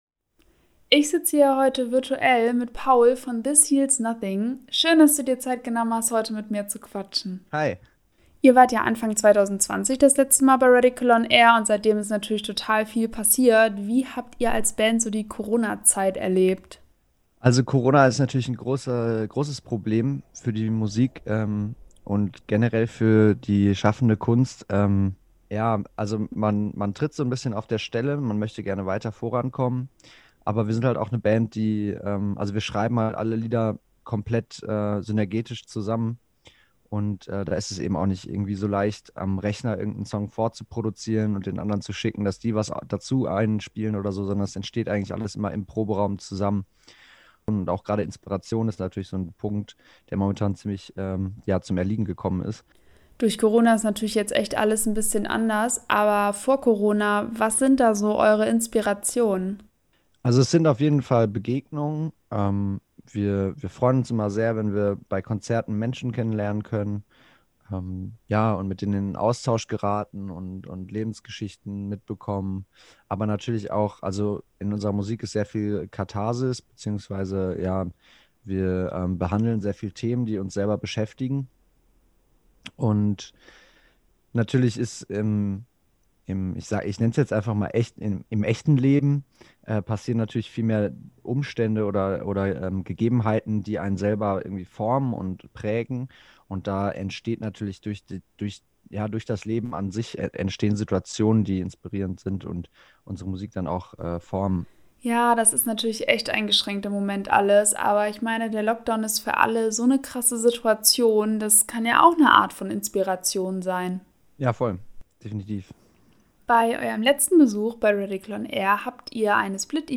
Interview mit THIS HEALS NOTHING